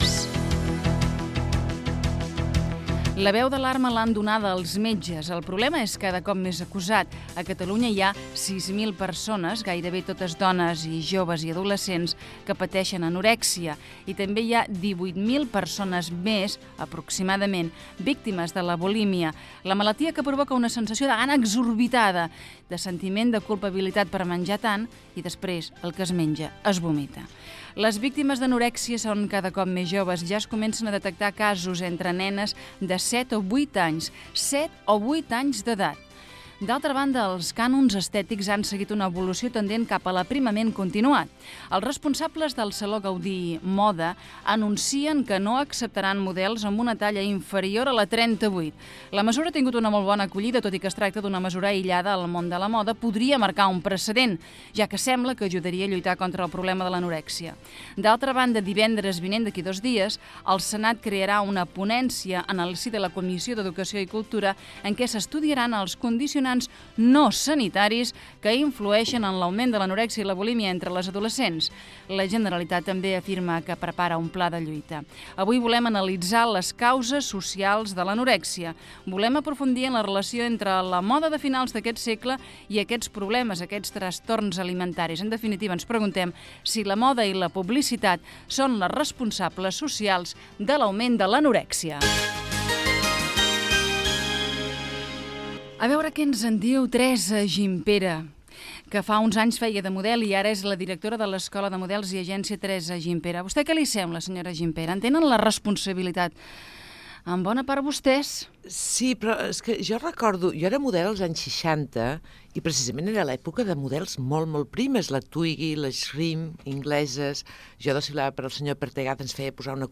Fragment d'una entrevista
Entreteniment